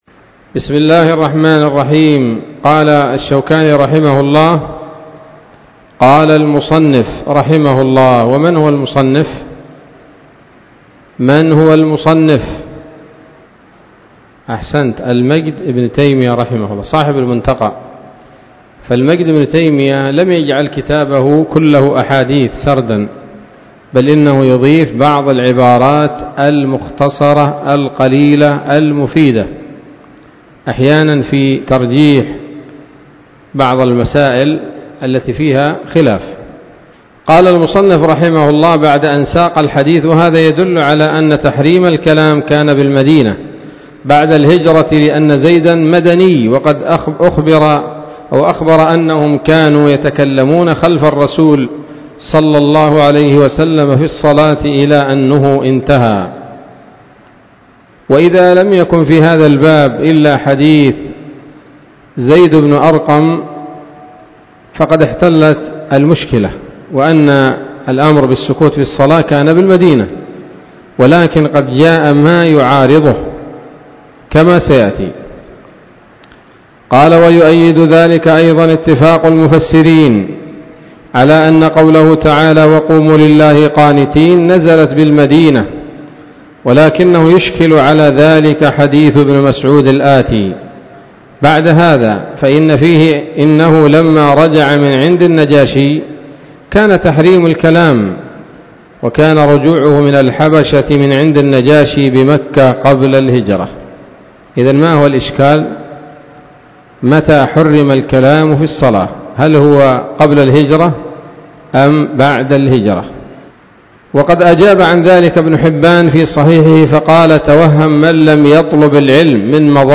الدرس الثالث من أبواب ما يبطل الصلاة وما يكره ويباح فيها من نيل الأوطار